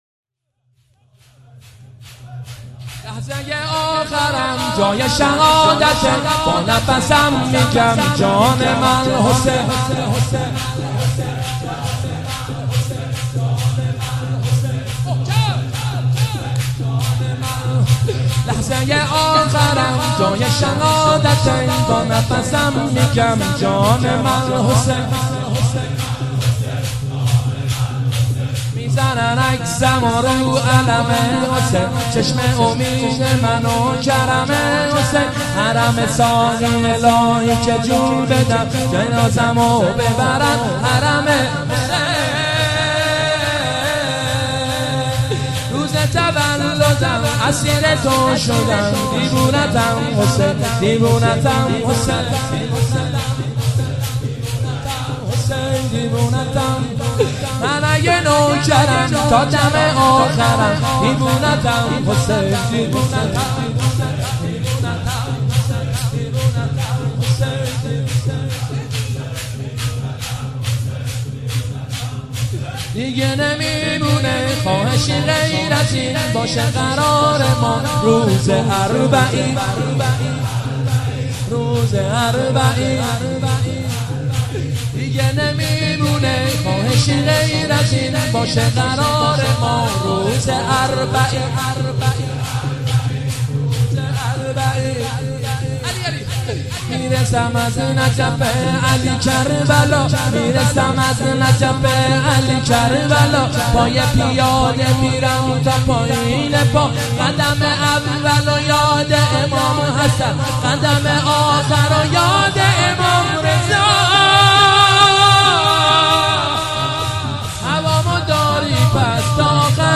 دانلود مداحی جدید
شور